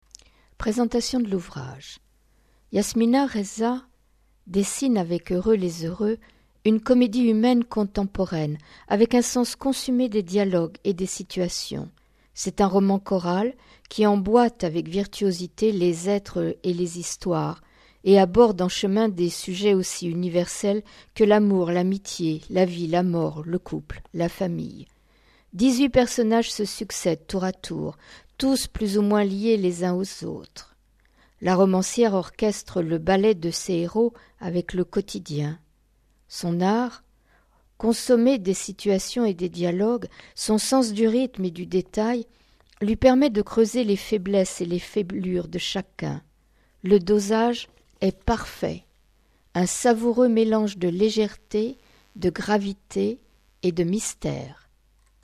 QUELQUES EXTRAITS D'AUDIOLIVRES ET D'AUDIOREVUES A ECOUTER